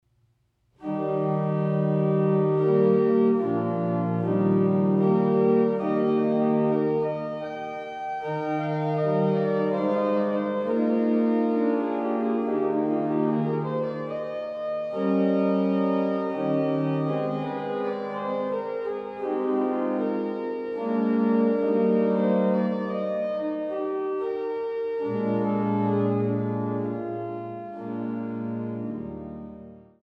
Stadtkirche St. Petri zu Löbejün